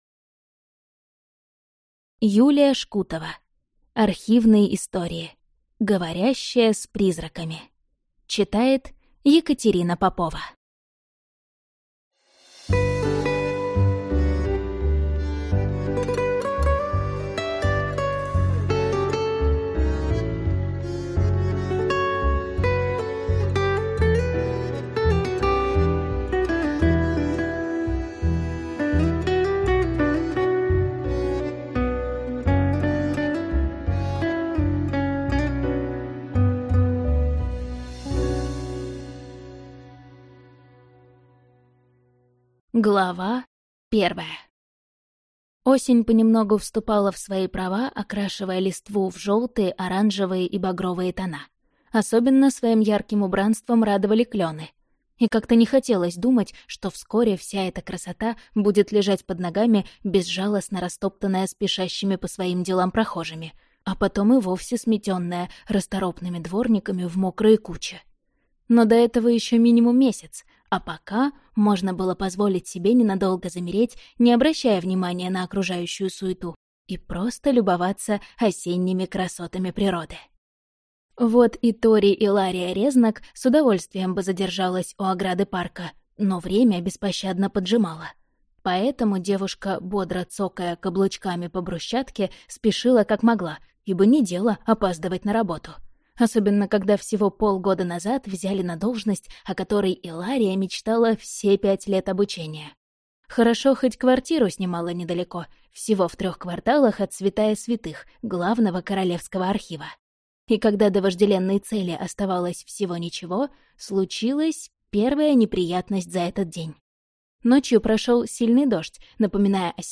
Аудиокнига Архивные истории. Говорящая с призраками | Библиотека аудиокниг